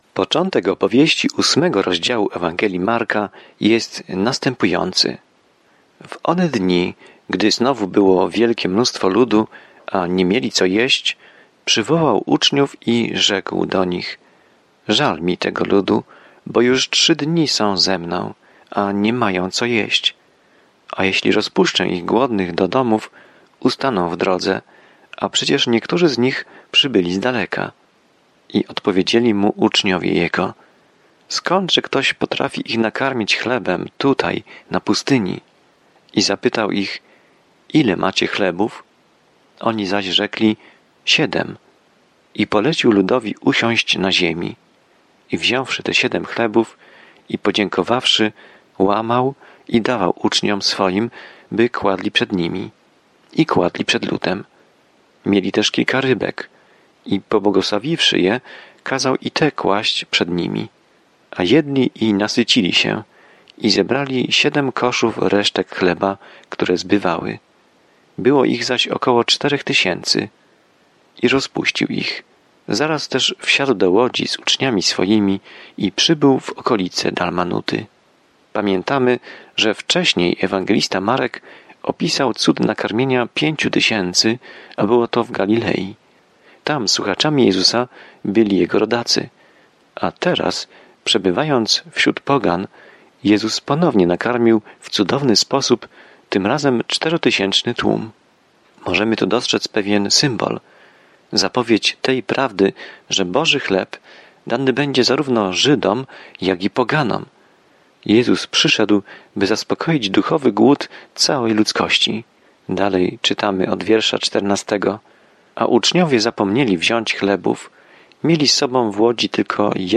Pismo Święte Marka 8 Dzień 8 Rozpocznij ten plan Dzień 10 O tym planie Krótsza Ewangelia Marka opisuje ziemską służbę Jezusa Chrystusa jako cierpiącego Sługi i Syna Człowieczego. Codziennie podróżuj przez Marka, słuchając studium audio i czytając wybrane wersety ze słowa Bożego.